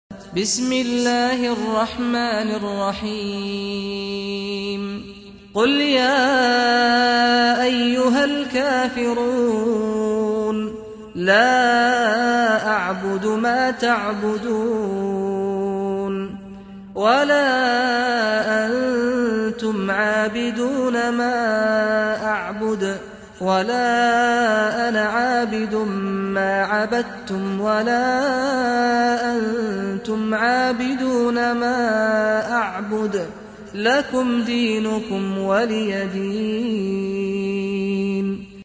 سُورَةُ الكَافِرُونَ بصوت الشيخ سعد الغامدي